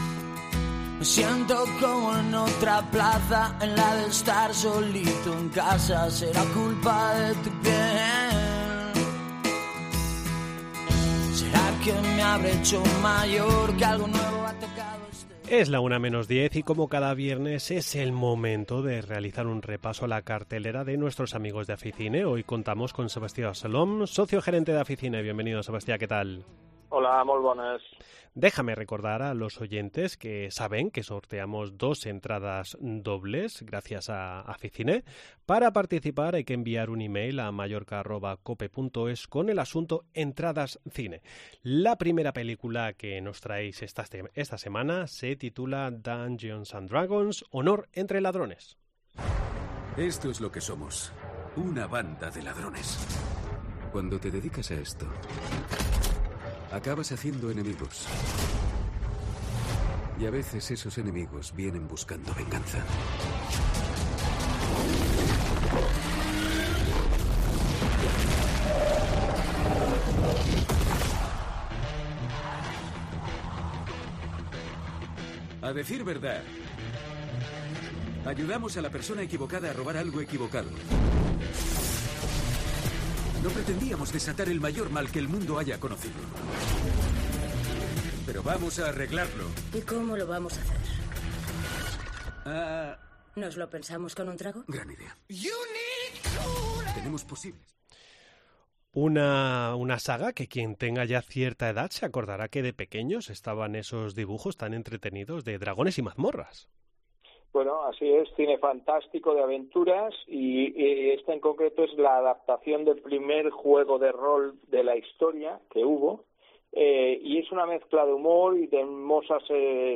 . Entrevista en La Mañana en COPE Más Mallorca, viernes 31 de marzo de 2023.